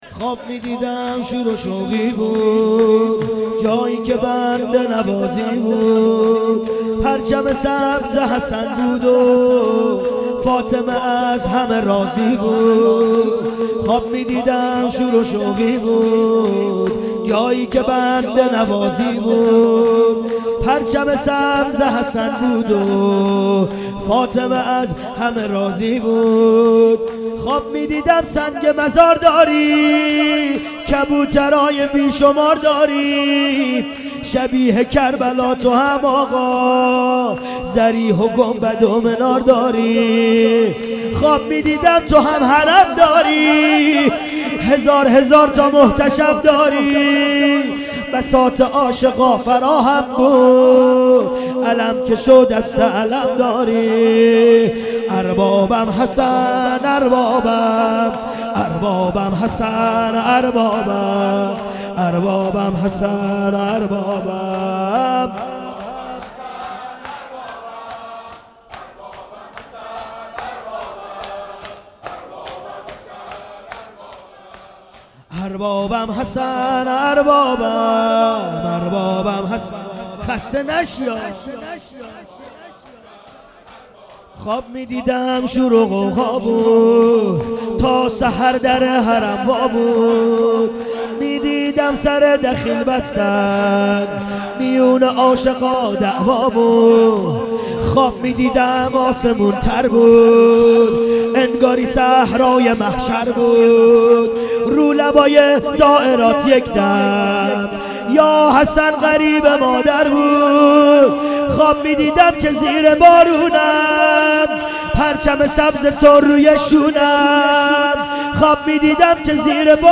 مناسبت : شهادت حضرت رقیه س
مکان : مشهد مقدس - بلوار پیروزی
مسجد و حسینیه حضرت ابوالفضل ع
===== فایل های صوتی مراسم =====
شور امام حسن مجتبی ع